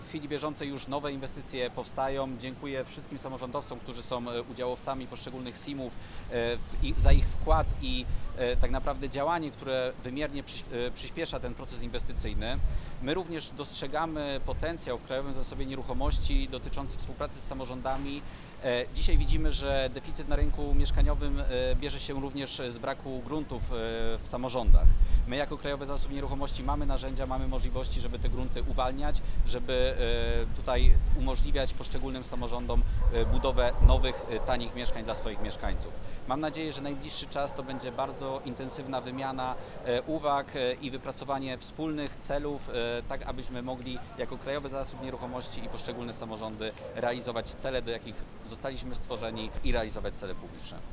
Ostatnim z tematów podejmowanych podczas konferencji była kwestia podejmowanych inwestycji mieszkaniowych we Wrocławiu.